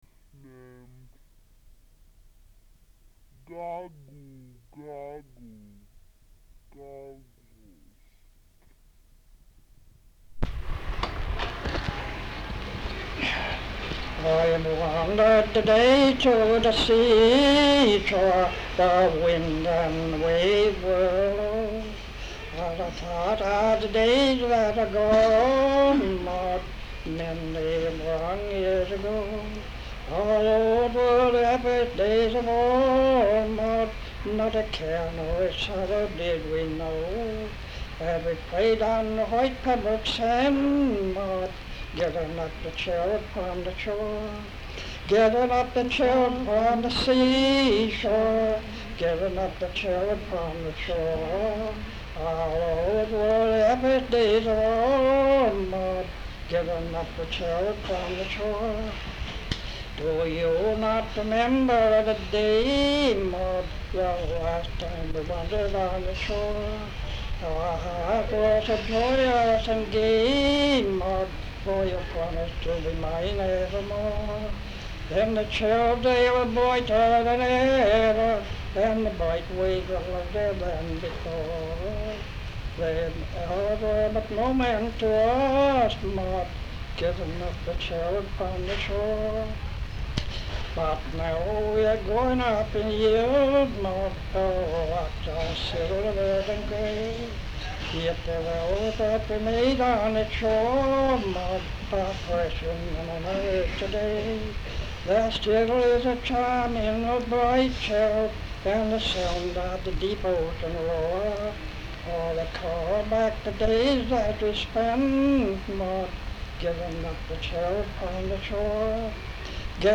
Folk songs, English--Vermont
sound tape reel (analog)
Location Windham, Connecticut